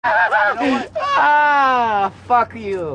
Sarcastic Laugh